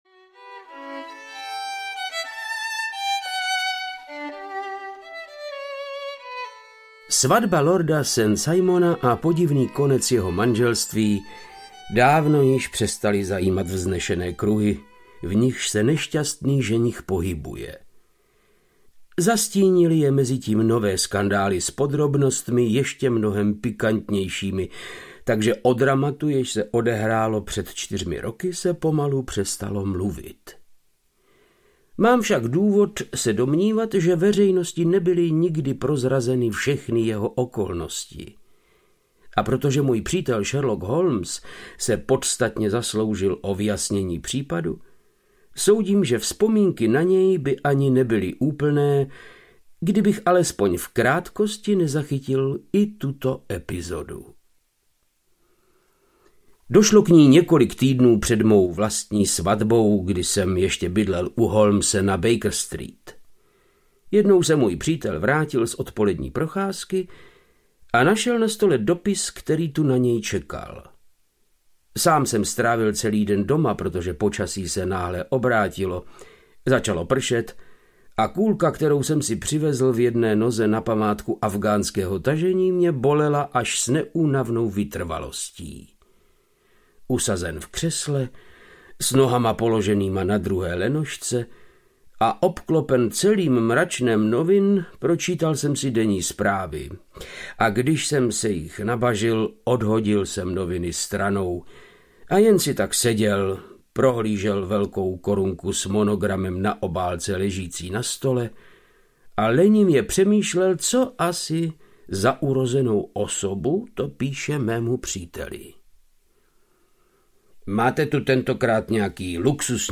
Urozený ženich audiokniha
Ukázka z knihy
• InterpretVáclav Knop
urozeny-zenich-audiokniha